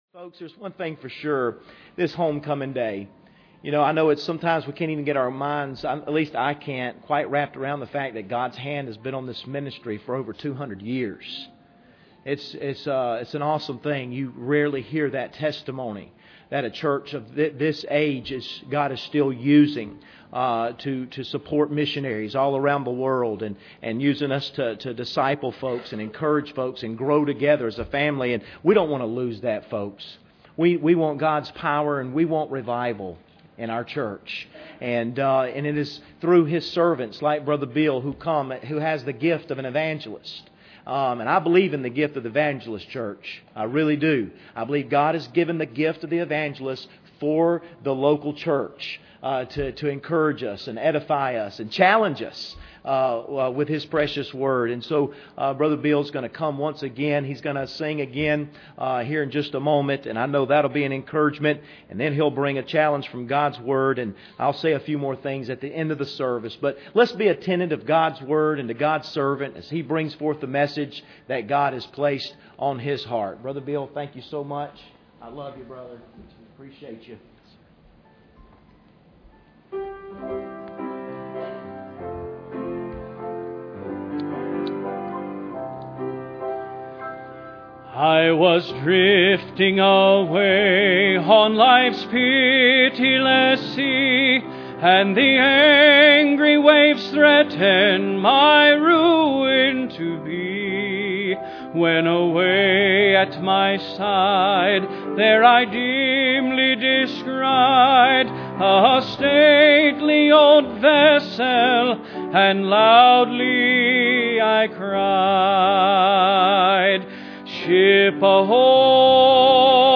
Jeremiah 2:1-9 Service Type: Sunday Evening Bible Text